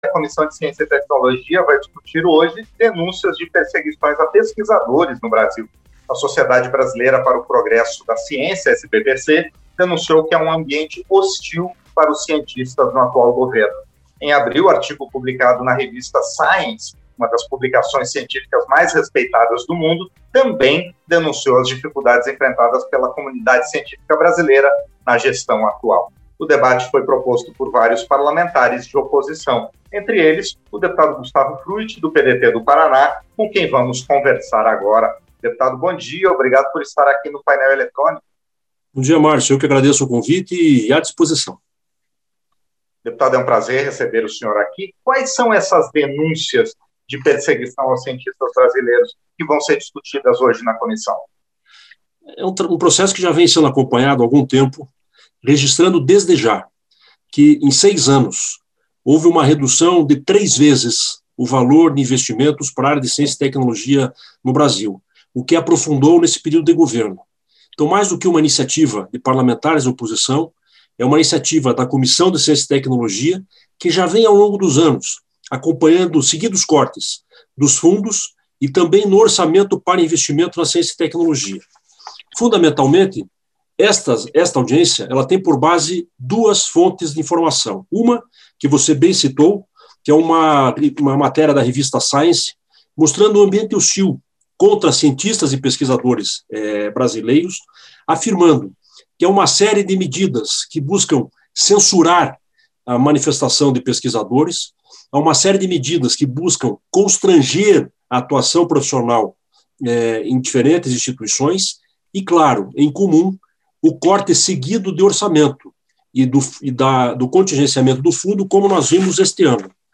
Entrevistas - Dep. Gustavo Fruet (PDT-PR)